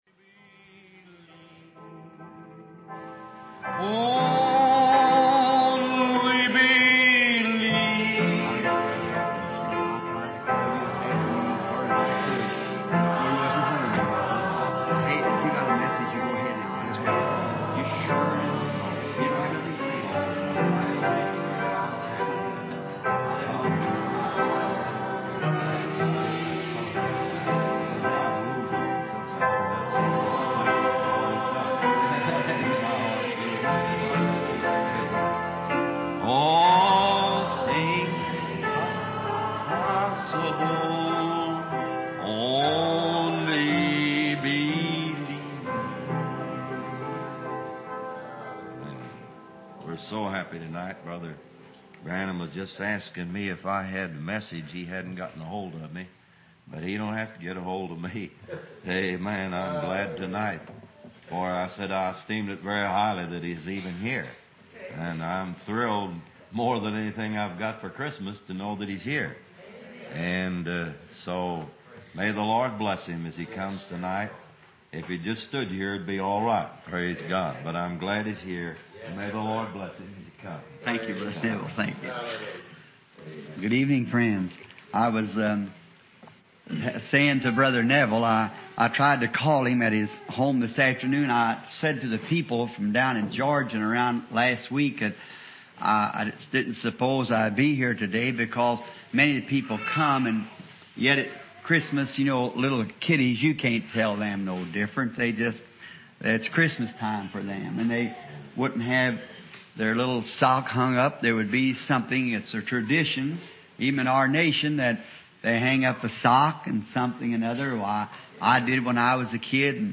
Dieses Portal gibt Ihnen die Möglichkeit, die ca. 1200 aufgezeichneten Predigten von William Marrion Branham aufzurufen, zu lesen oder zu hören.